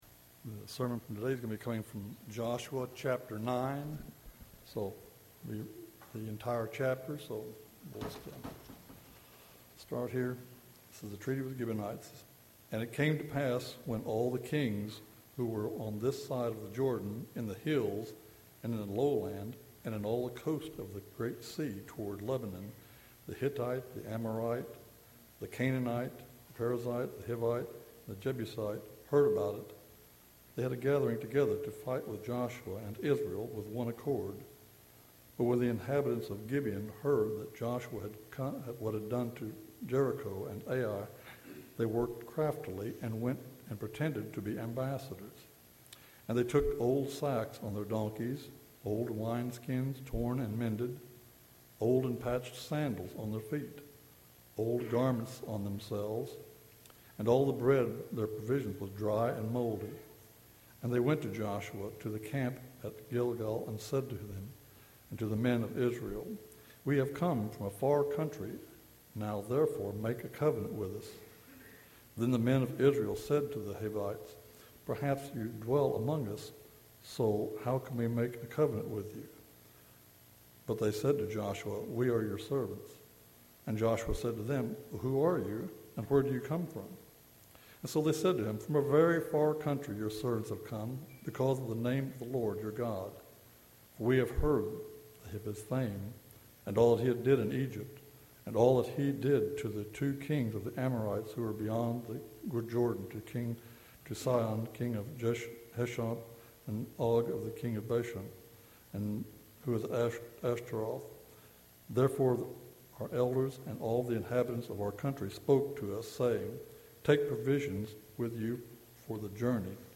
A sermon in a series on the book of Joshua.